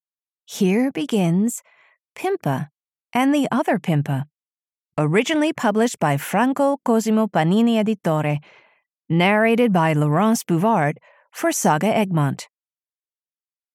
Pimpa - Pimpa and the Other Pimpa (EN) audiokniha
Ukázka z knihy